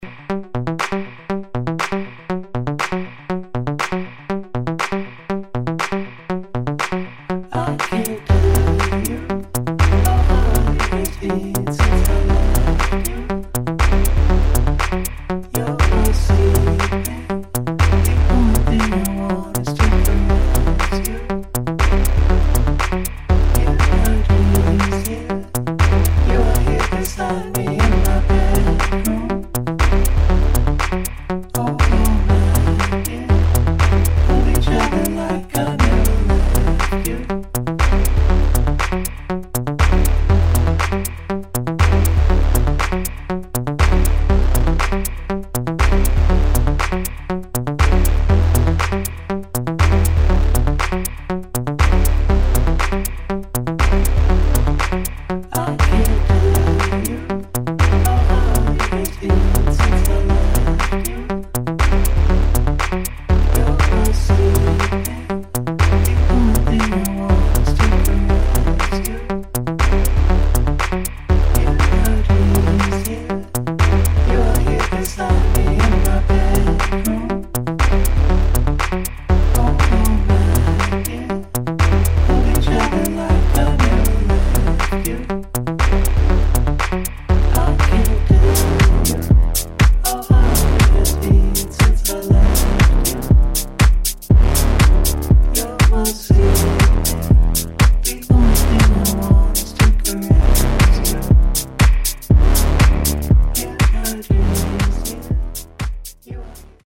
[ ELECTRONIC / HOUSE / TECHNO ]